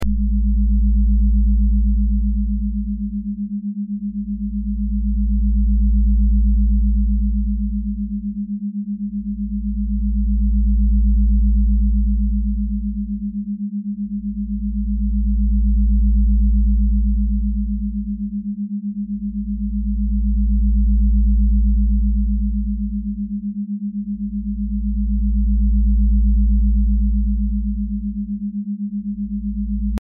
🌍 Pure 7.83Hz Schumann Resonance sound effects free download
Experience the pure 7.83 Hz Schumann Resonance—the natural heartbeat of the Earth—layered with binaural beats designed to align your mind and body with the planet’s frequency. This grounding sound harmonizes your brainwaves, reduces stress, and promotes deep relaxation in just moments.